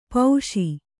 ♪ pauṣi